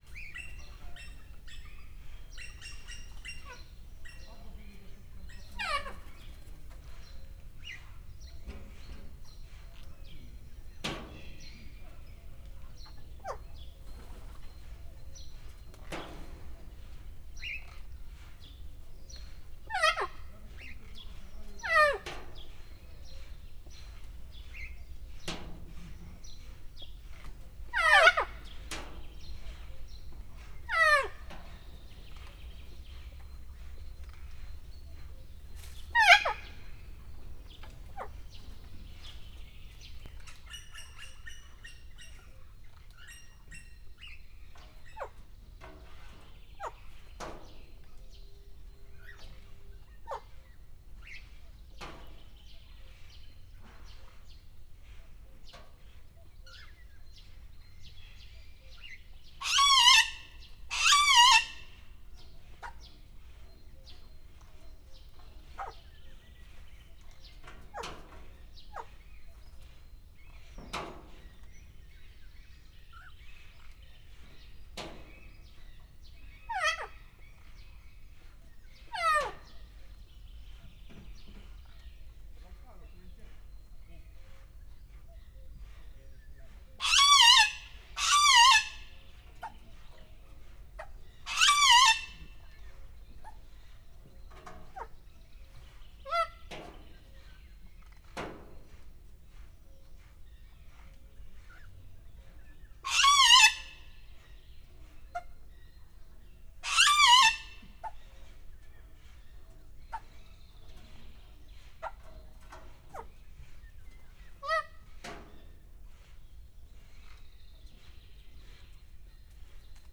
csupaszszemukakadu03.25.wav